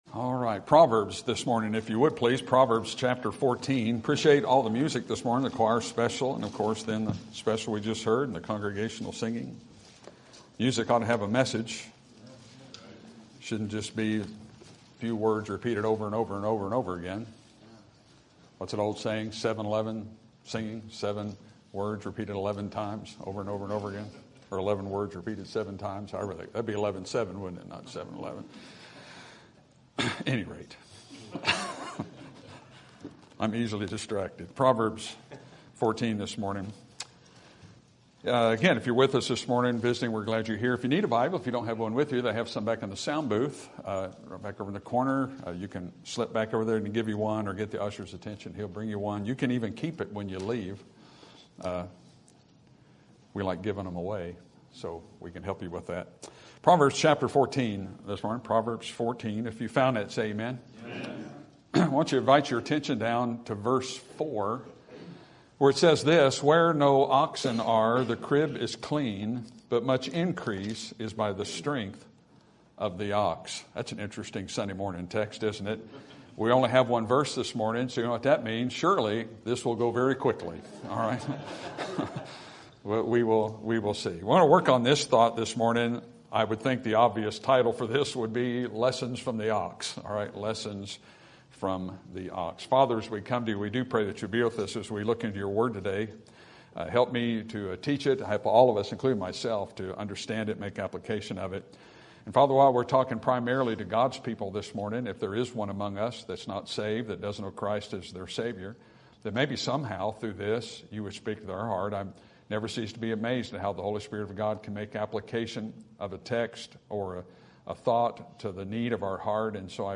Sermon Topic: General Sermon Type: Service Sermon Audio: Sermon download: Download (19.77 MB) Sermon Tags: Proverbs Ox Field Patience